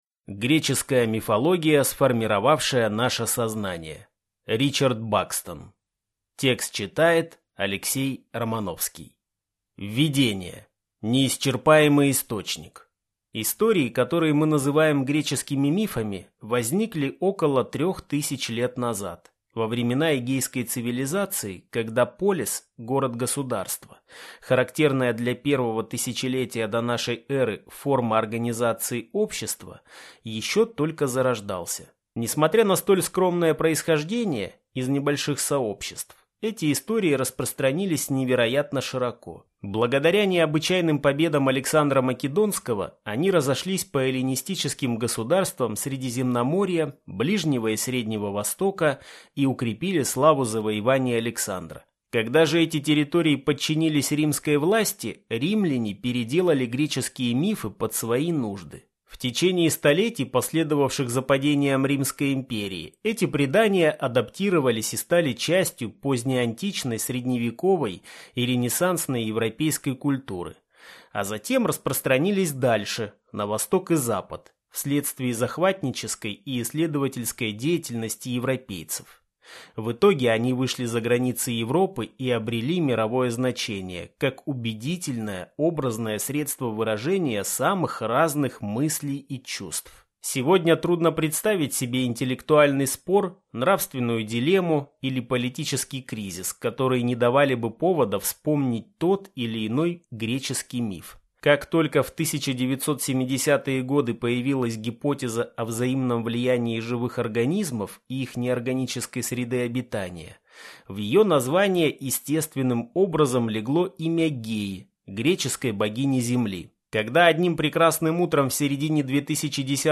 Аудиокнига Греческая мифология, сформировавшая наше сознание | Библиотека аудиокниг